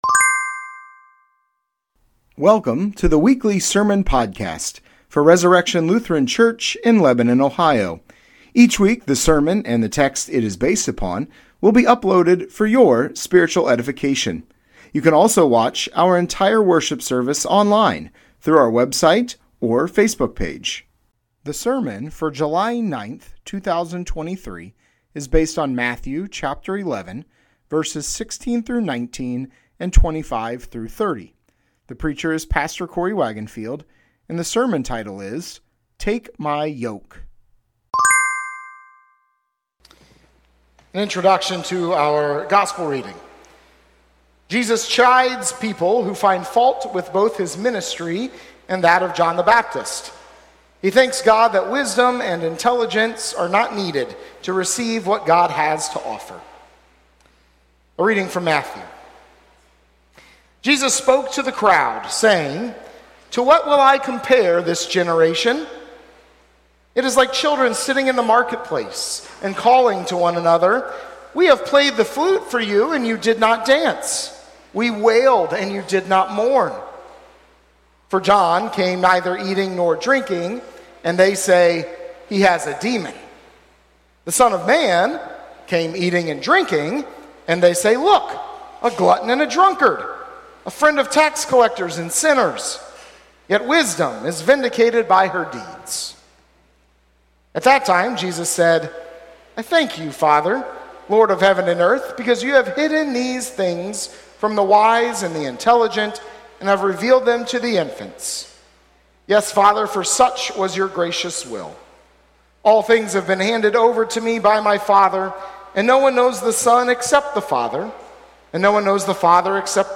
Sermon Podcast Resurrection Lutheran Church - Lebanon, Ohio July 9, 2023 - "Take My Yoke"